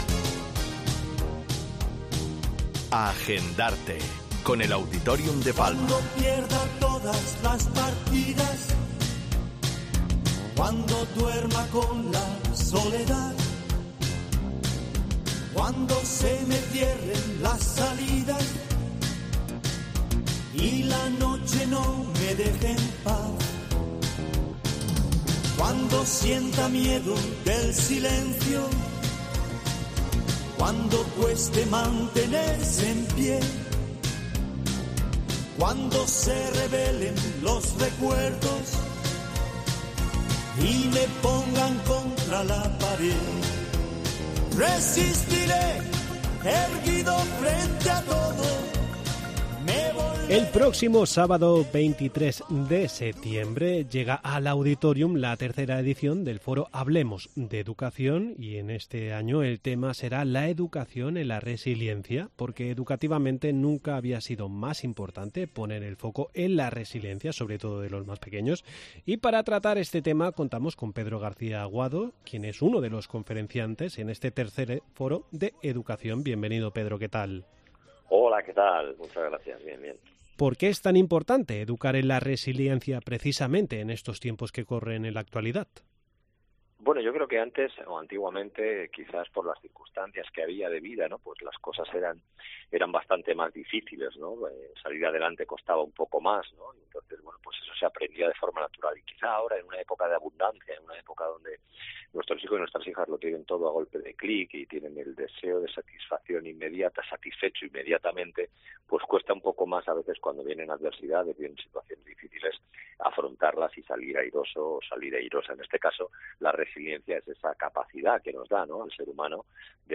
AgendARTE con Pedro García Aguado, conferenciante en el III Foro de Educación del Auditorium de Palma. Entrevista en 'La Mañana en COPE Más Mallorca', jueves 31 de agosto de 2023.